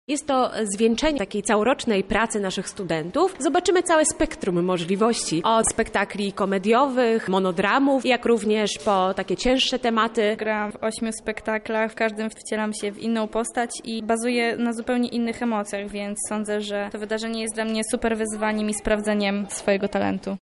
„Tematyka spektakli jest różnorodna, każdy zaskoczy czymś innym” –  komentują studentki: